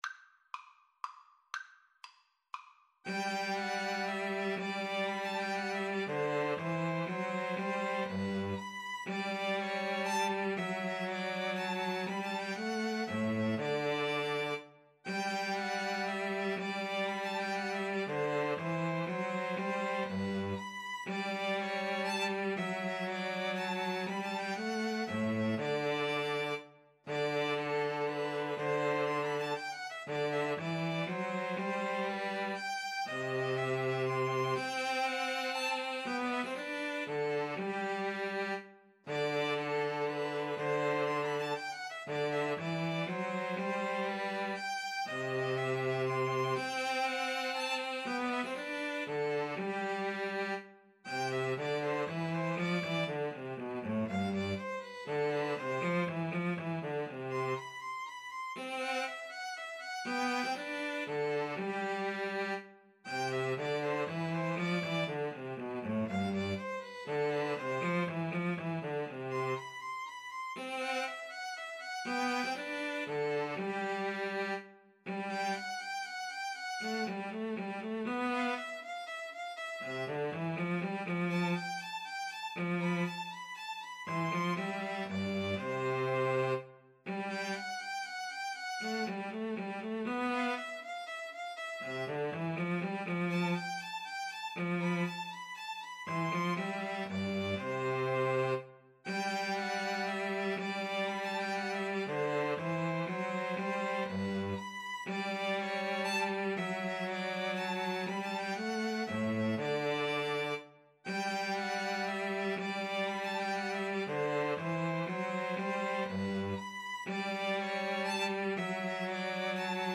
G major (Sounding Pitch) (View more G major Music for 2-Violins-Cello )
3/4 (View more 3/4 Music)
2-Violins-Cello  (View more Intermediate 2-Violins-Cello Music)
Classical (View more Classical 2-Violins-Cello Music)